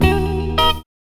4306R GTRRIF.wav